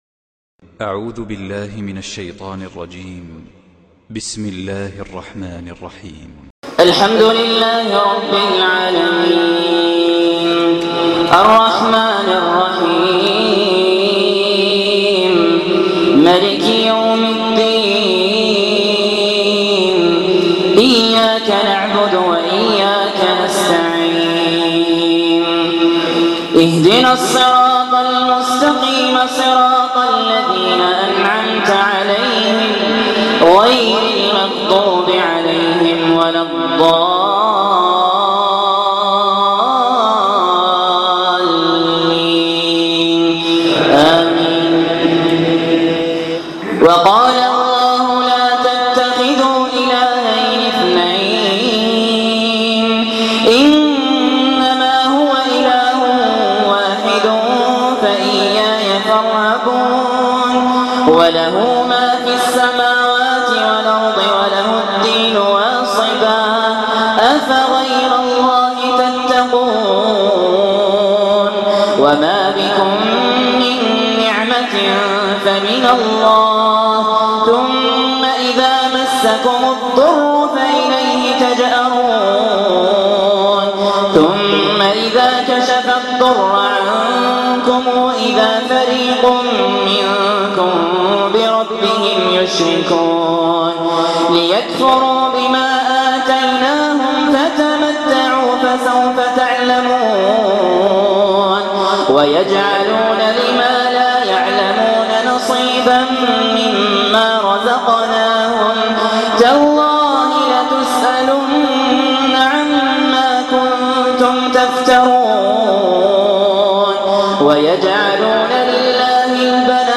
تلاوات من الصلوات الجهرية